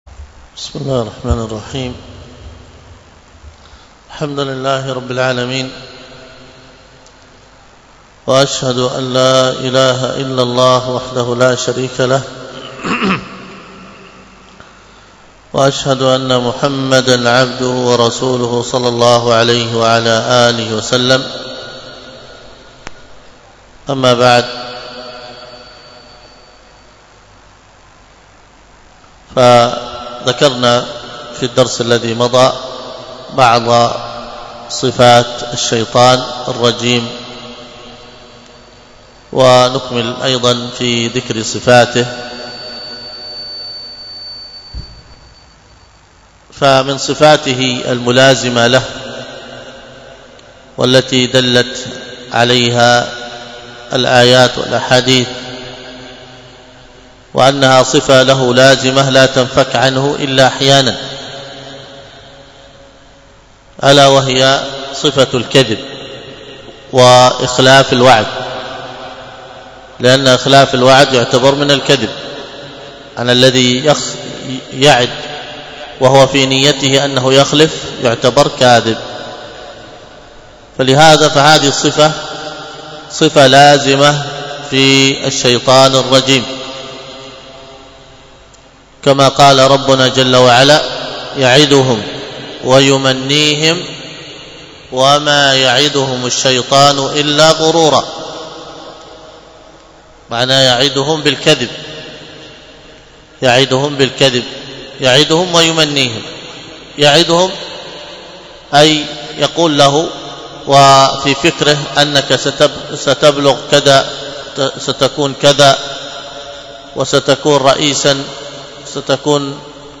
الدرس في تعليق - الرد على الأخنائي 13، الدرس الثالث عشر : من : (وهؤلاء الذين يعتقدون أ، القبور تنفعهم وتدفع البلاء عنهم ...